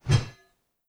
sword_woosh_2.wav